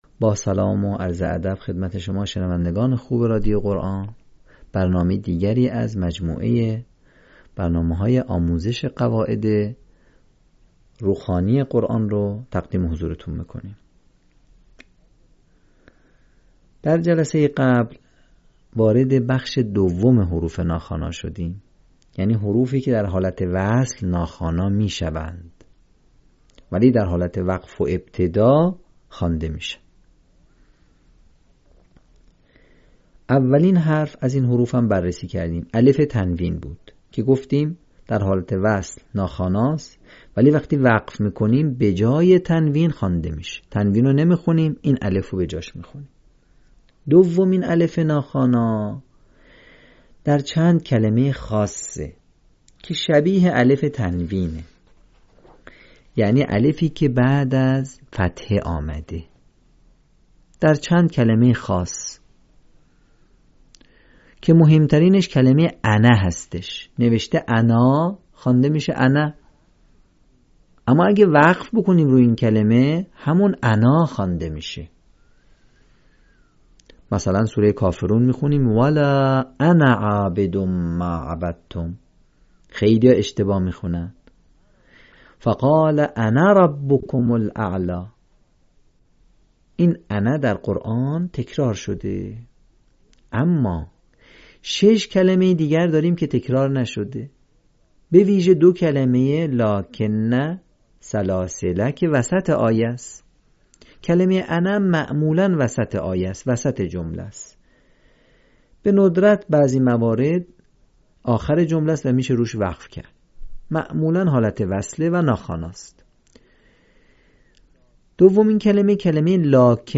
صوت | آموزش روخوانی «الف وصل»